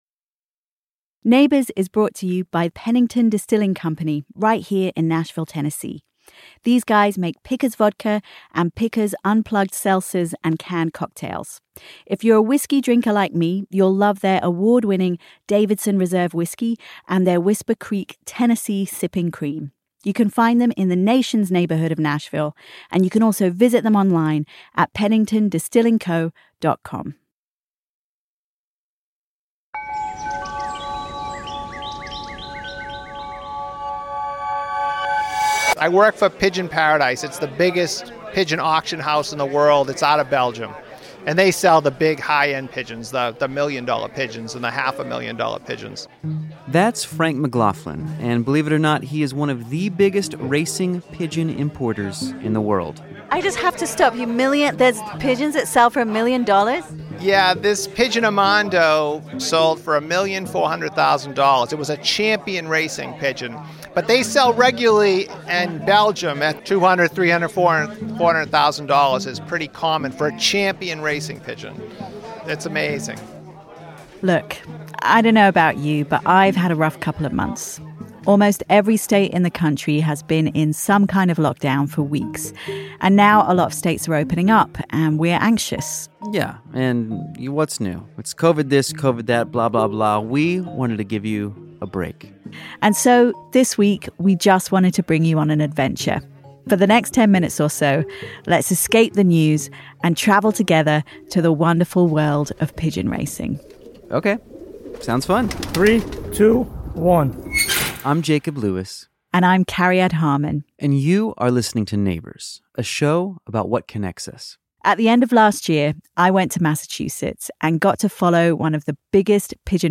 As always this episode also contains YOUR voices from the Neighbors “Reverse Complaint” Line sharing how you’re doing.